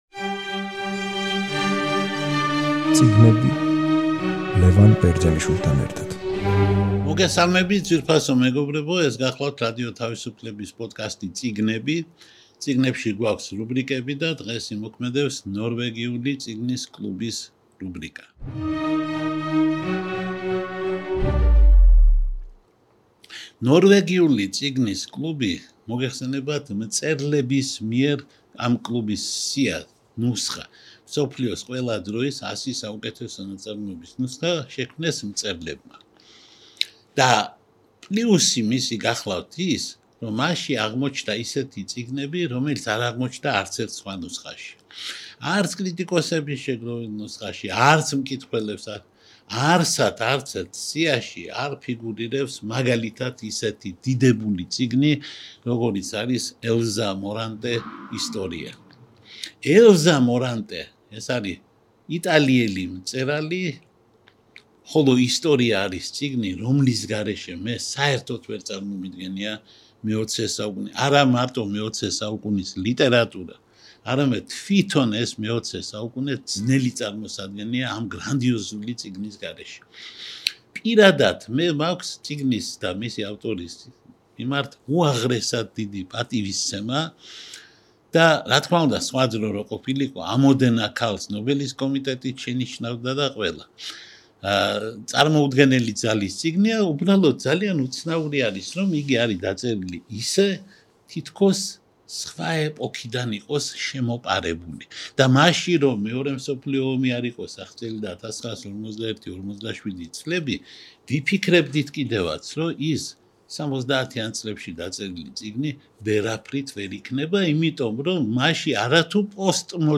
რადიო თავისუფლების პოდკასტი „წიგნები“ რუბრიკით „ნორვეგიული წიგნის კლუბი“ გთავაზობთ საუბარს იტალიელ პოეტსა და მწერალ ელზა მორანტეზე და მის 1974 წელს გამოცემულ რომანზე „ისტორია“, რომელმაც იტალიაში მწვავე დისკუსია გამოიწვია. ელზა მორანტე 1912 წელს რომში დაიბადა ღარიბ ოჯახში. ძალიან ადრე წავიდა...